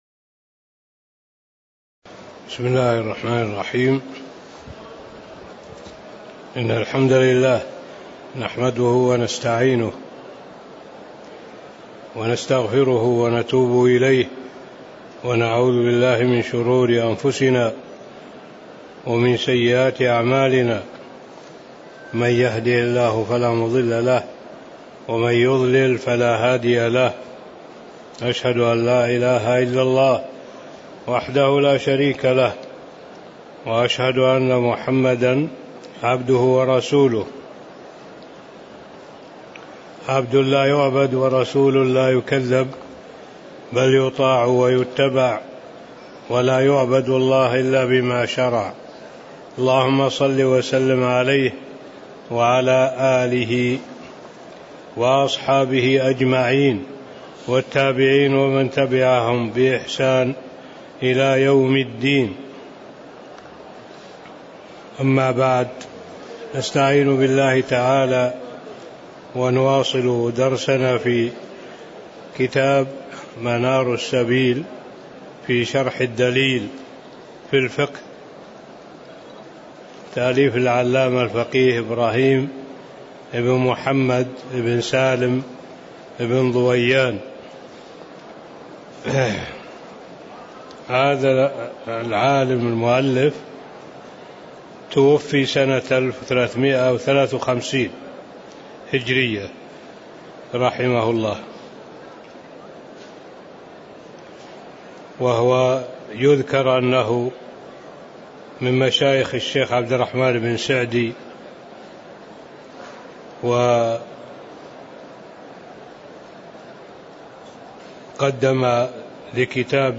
تاريخ النشر ٤ صفر ١٤٣٧ هـ المكان: المسجد النبوي الشيخ: معالي الشيخ الدكتور صالح بن عبد الله العبود معالي الشيخ الدكتور صالح بن عبد الله العبود كتاب الحجر فصل فيما تبطل به الوكالة (02)